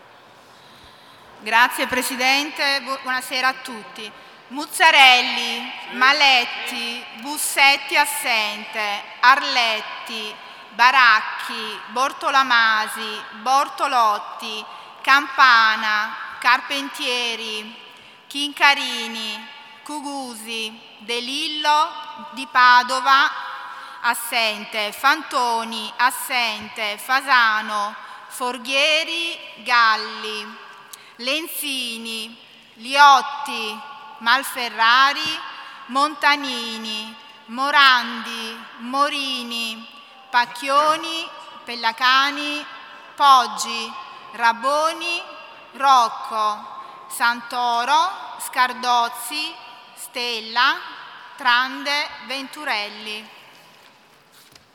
Seduta del 17/01/2018 Appello.